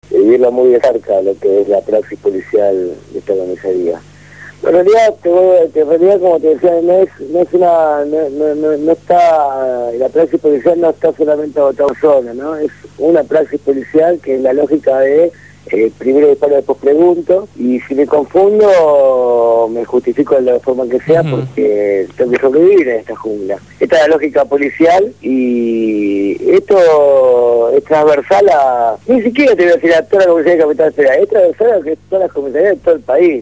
Hoy fue entrevistado